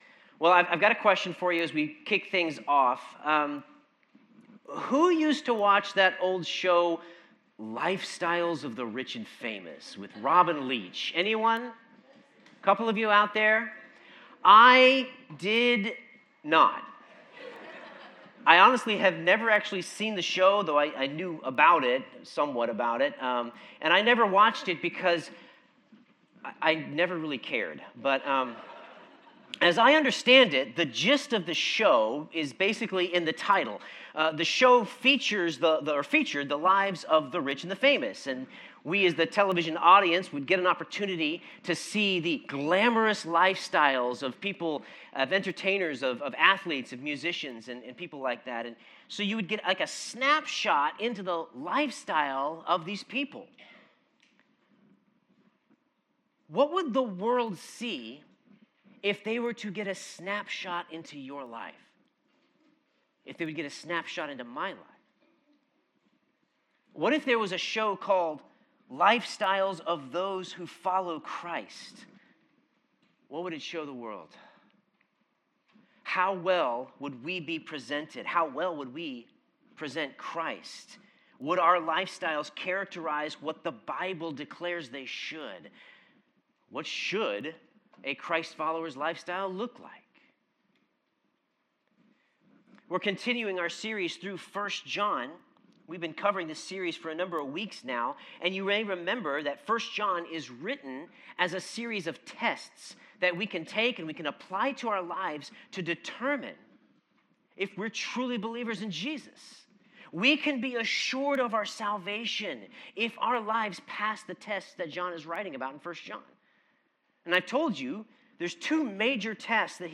Sermon Notes The lifestyle of a Christ-follower should be characterized by love.